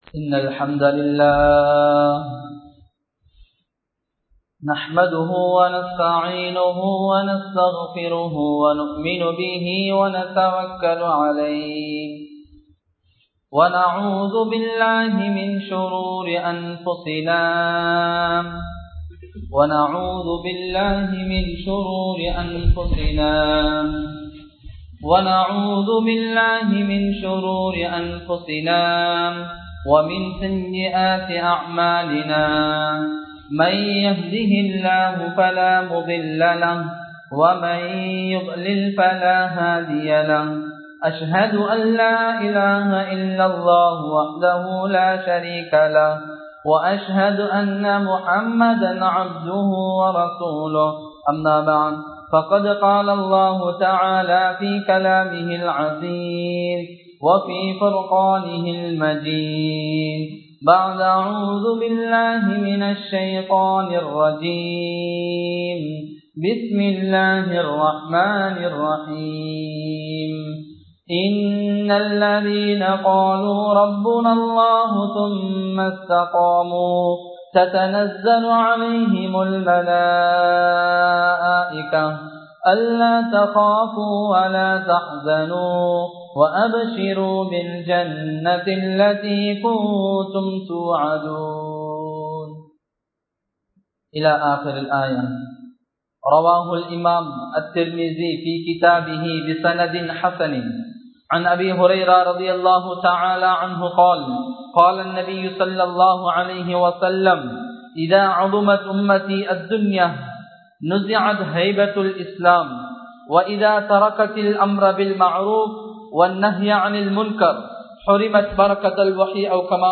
கியாமத் நாளின் அடையாளங்கள் (Sighns of the day of Resurrection) | Audio Bayans | All Ceylon Muslim Youth Community | Addalaichenai